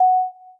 Cam_Start.ogg